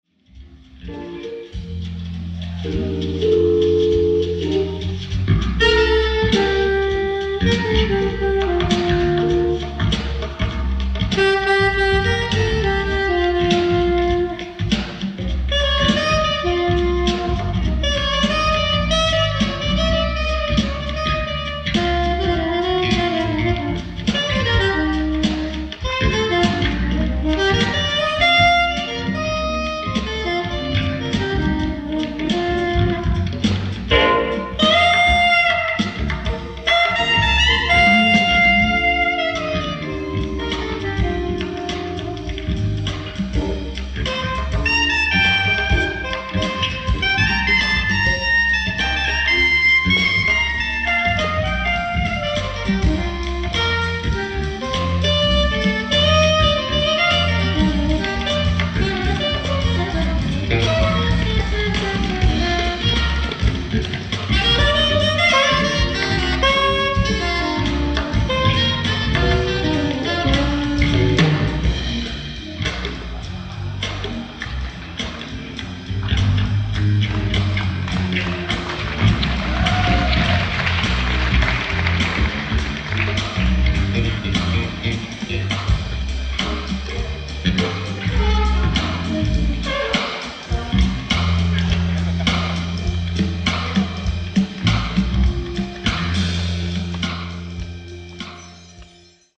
3,500円 ライブ・アット・ハマースミス・オデオン、ロンドン 04/28/1983 新たに発掘された新音源！！